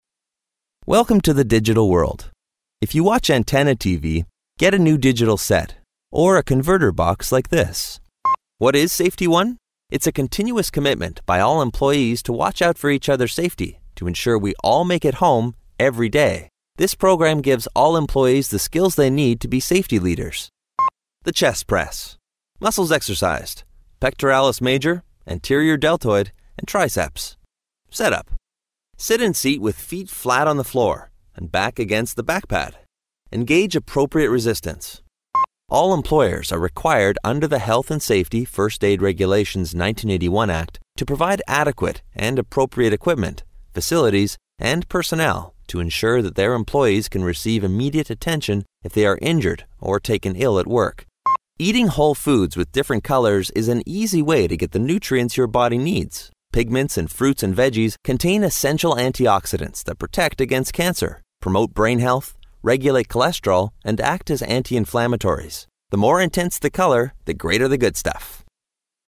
authentic, honest, believable Midwestern voice, All-American Approachable Articulate Attractive Authoritative Believable Casual Charming Classy Clear Comedic Comforting Confident Conversational Cool Corporate Crisp Direct Down to earth Dynamic Educated Energetic Engaging Friendly Funny Generation X Generation Y Genuine Guy Next Door Happy Honest Informative Intelligent Mature Midwest Midwestern Modern National Natural Neutral Refreshing Sincere Smart Smooth Trustworthy Upbeat Versatile Youthful.
Sprechprobe: eLearning (Muttersprache):